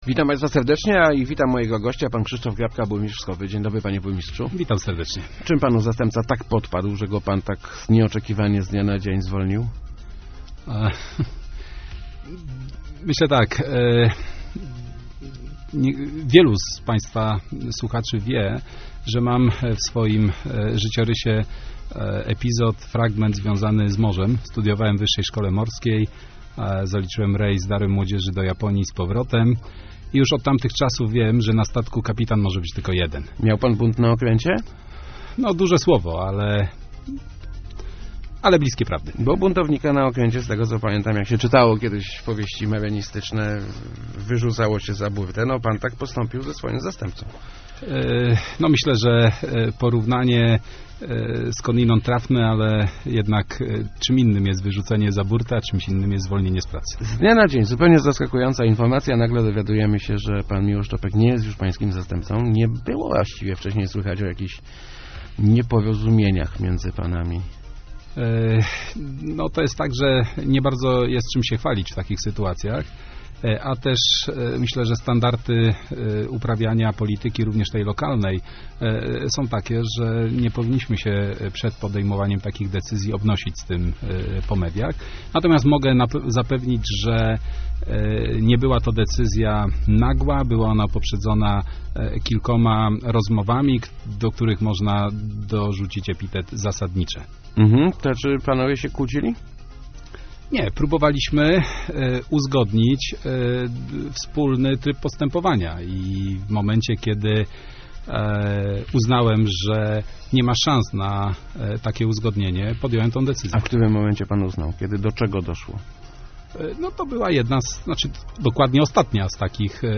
Pan Mi�osz Czopek nie gra� z nami w jednej dru�ynie - mówi� w Rozmowach Elki burmistrz Wschowy Krzysztof Grabka. Jak ujawini�, zwolnienie zast�pcy spowodowane by�o konfliktami personalnymi i przypisywaniem sobie zas�ug w dzia�aniu samorz�du.